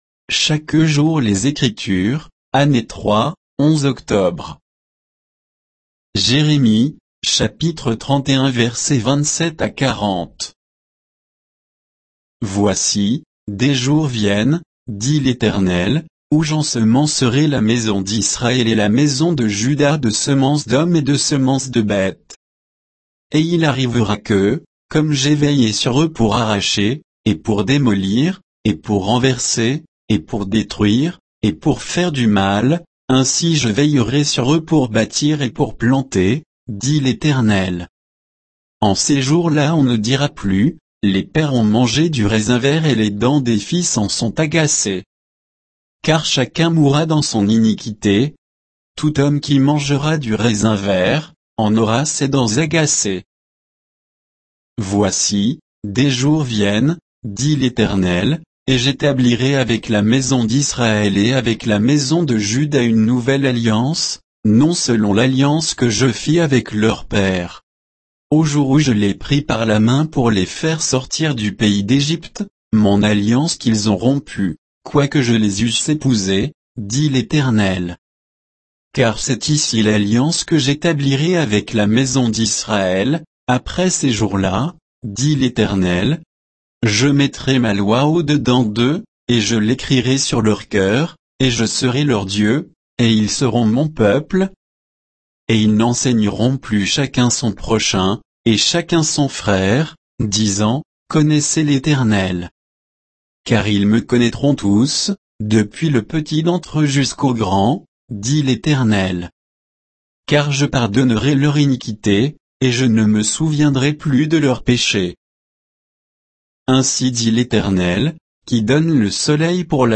Méditation quoditienne de Chaque jour les Écritures sur Jérémie 31, 27 à 40